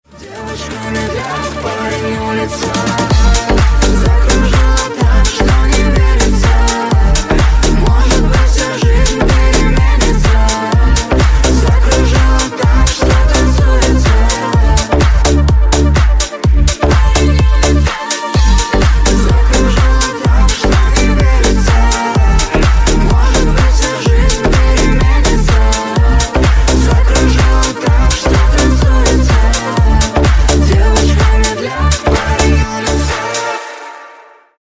Клубные Рингтоны
Рингтоны Ремиксы » # Танцевальные Рингтоны